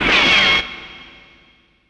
Slam3.wav